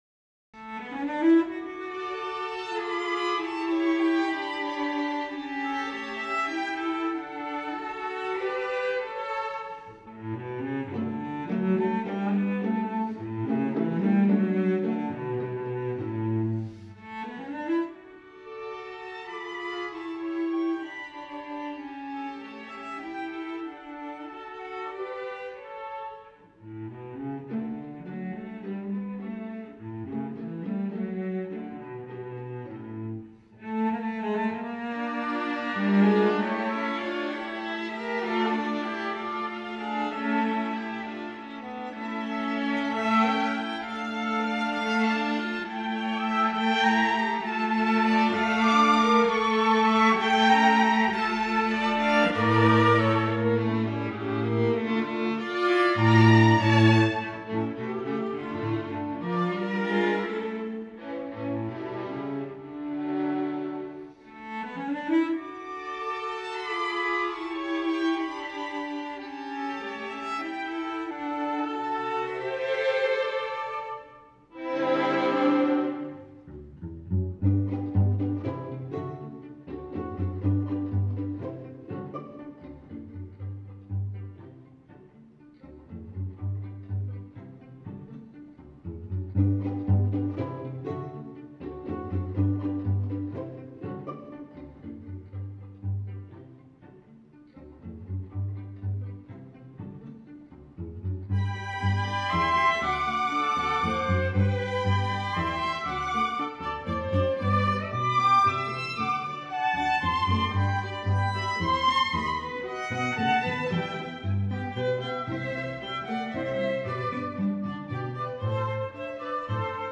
violines
viola
cello